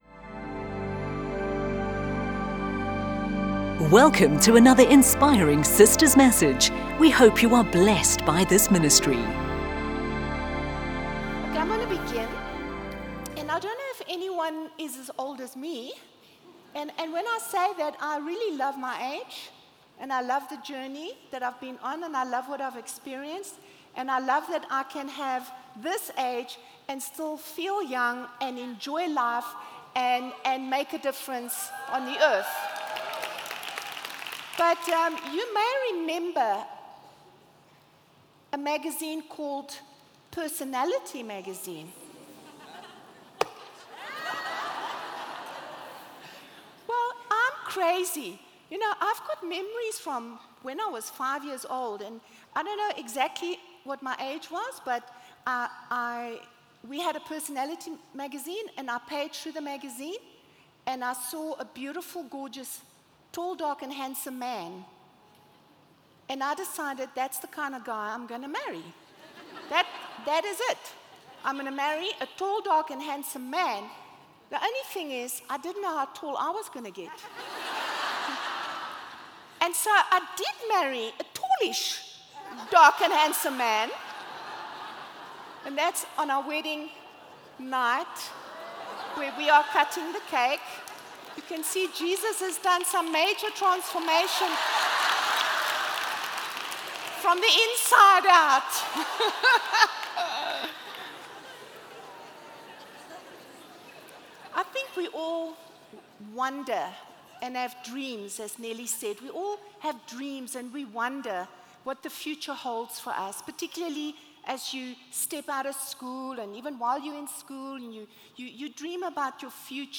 You can download our weekend messages for free!